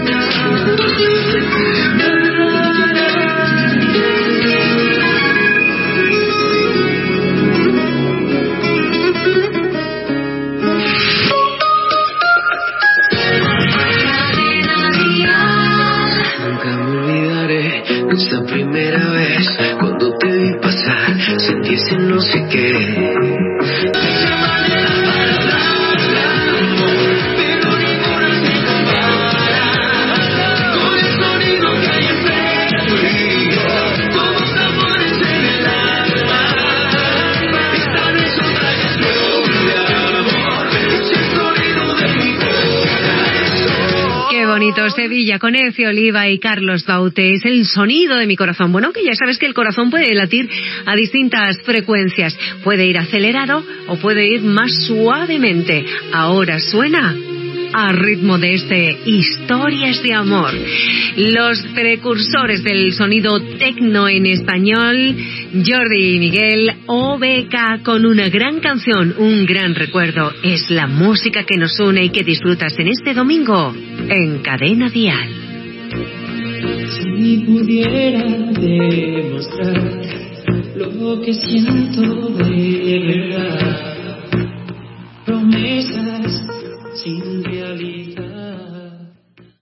Tema musical, indicatiu de la ràdio, tema i presentació del següent
Musical
FM